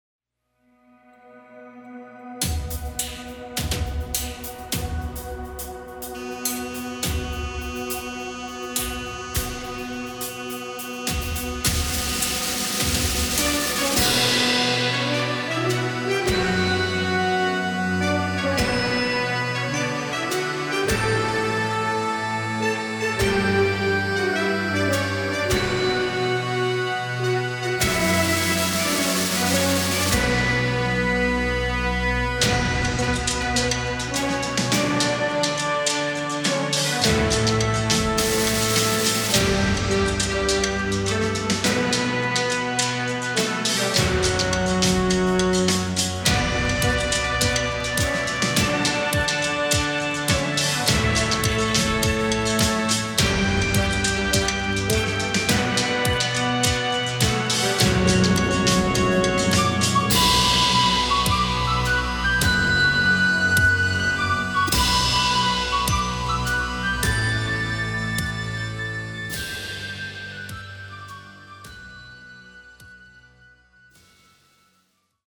nous nous revigorons avec un hymne martial et victorieux